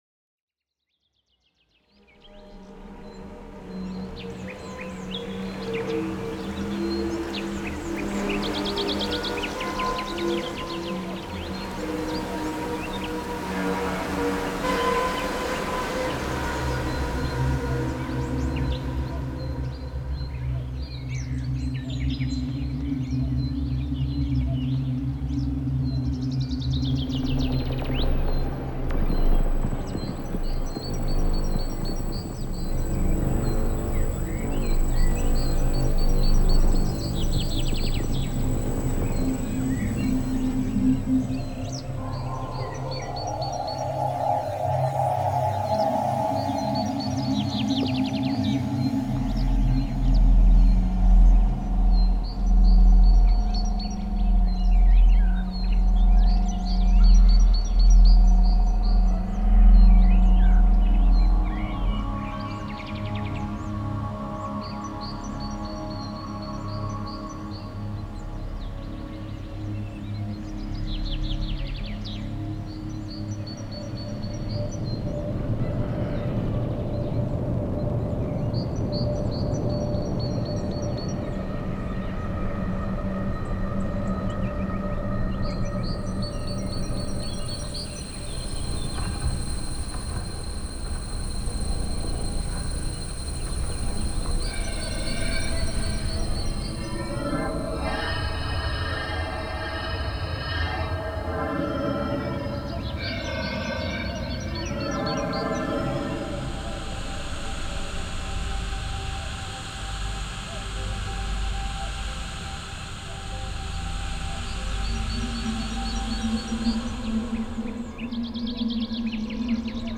Genre:Cinematic
さりげない動き。
深い質感。
Abstract Bedsは、サウンドデザインに奥行き、トーン、そして感情的な空間を加えるために設計された、65種類のテクスチャー系アンビエントレイヤーを収録しています。
ローファイなスタティックテクスチャー、変化するノイズベッド、穏やかなハーモニックの動きから構築されており、主張しすぎることなく、没入感のある背景や繊細な音のディテールを生み出すのに最適です。
温かみのあるサブハーモニックなアトモスフィア、スタティックな低域、ミニマルなトーナルベッド。
きらめくノイズレイヤー、フィルター処理されたテクスチャー、ざらついたエア系トーン。
ローファイなスタティックディテールと動きのない音色。
デモサウンドはコチラ↓